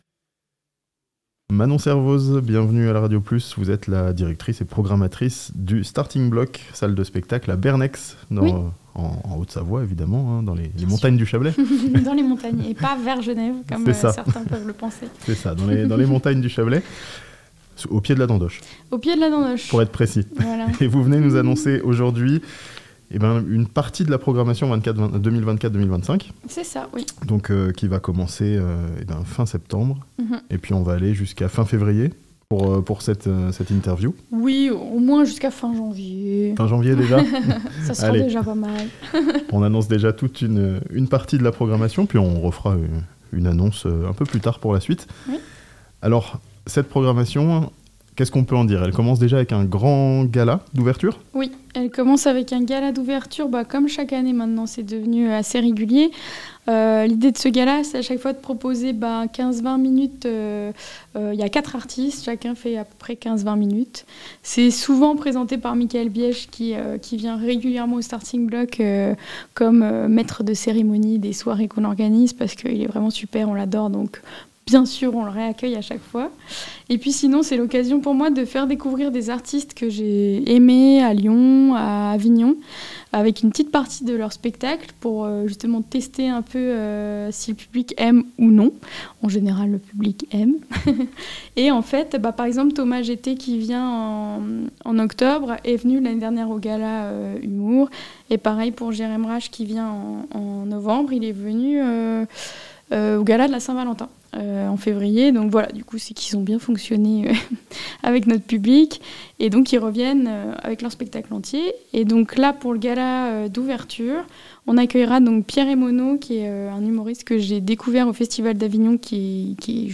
A Bernex, dans le Chablais, le Starting Block lance sa nouvelle saison (interview)